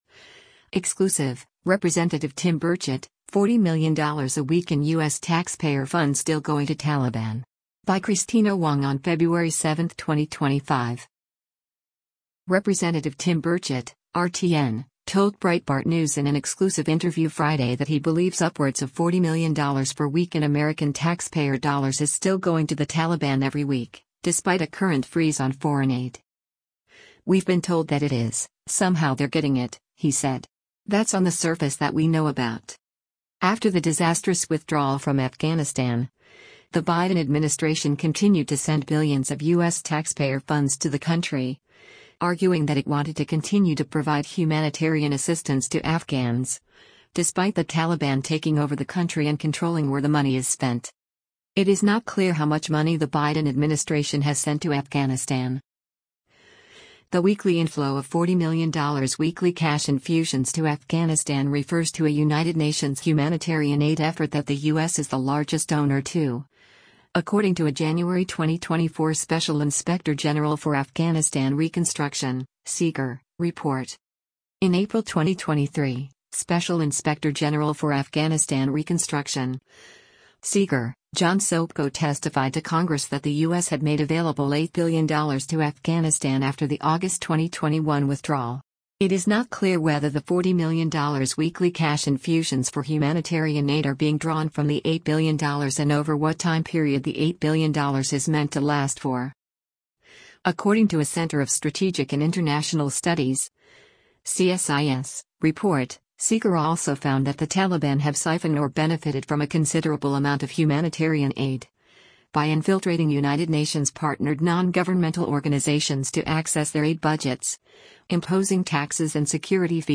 Rep. Tim Burchett (R-TN) told Breitbart News in an exclusive interview Friday that he believes upwards of $40 million per week in American taxpayer dollars is still going to the Taliban every week, despite a current freeze on foreign aid.